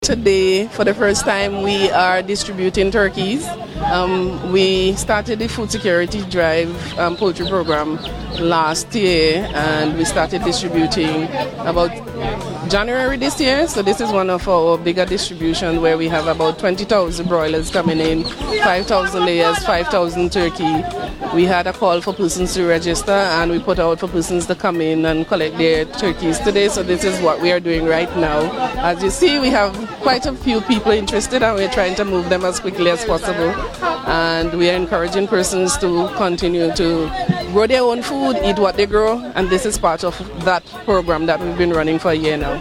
She was speaking to the Agency for Public Information the API, at the Dumbarton Agricultural Station in Mesopotamia on Friday, August 15 th , where one of the largest poultry distributions to date took place, including approximately 20,000 broiler chicks, 5,000 layers, and 5,000 turkeys.